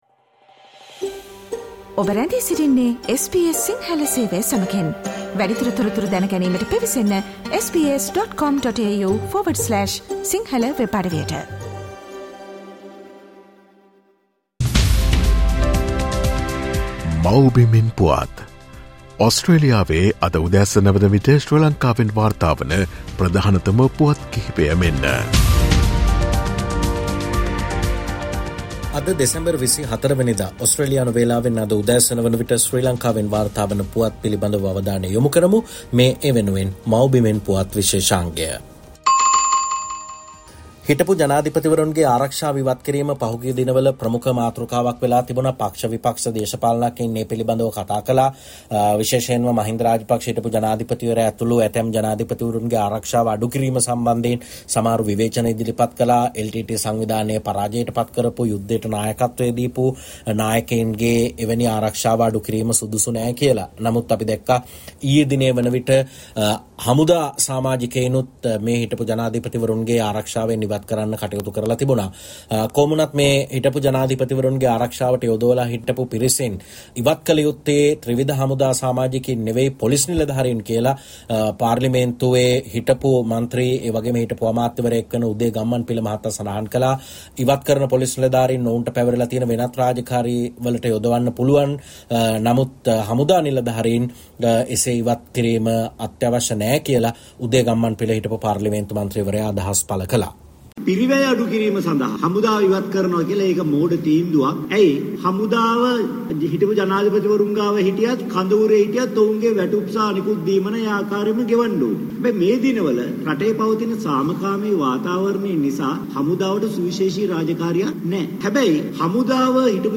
SBS Sinhala reporter and senior journalist